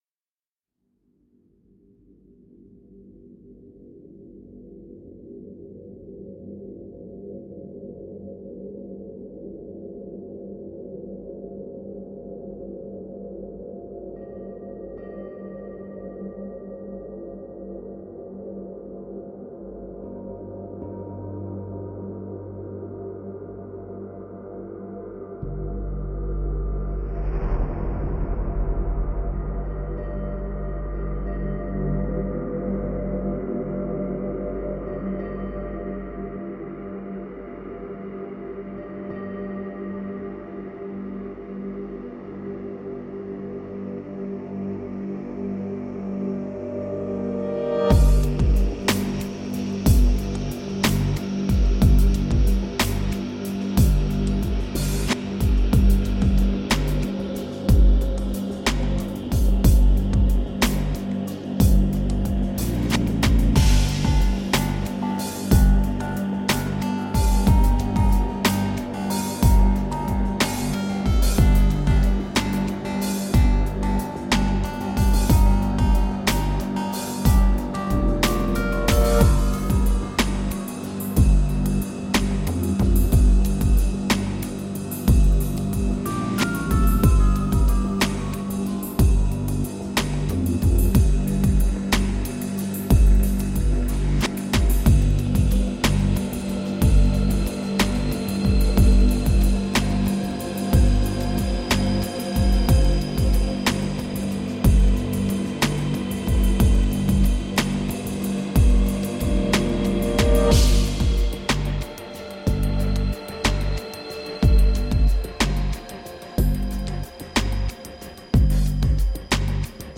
Intelligent ambient downtempo.